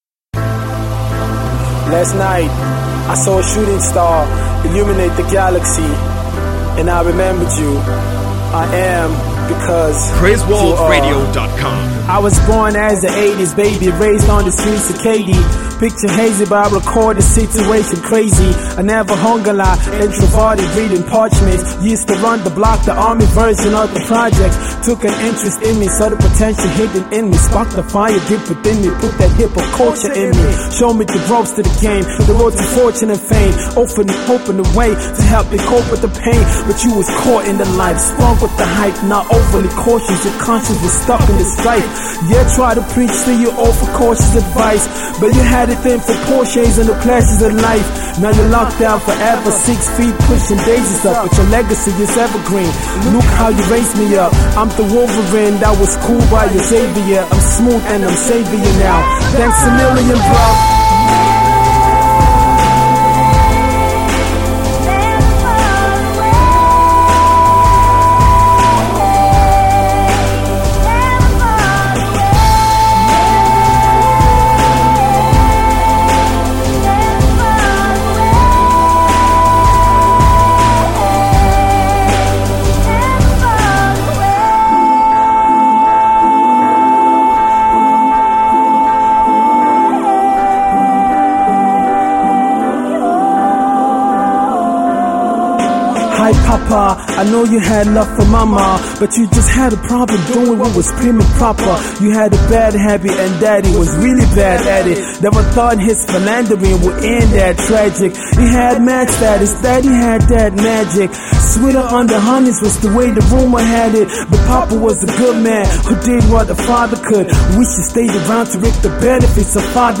awesome vocals
mid tempo hip hop track